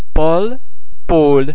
The French [ o ] sound can also be almost as open as the vowel sound in English words like otter, lot.
o_Paul.mp3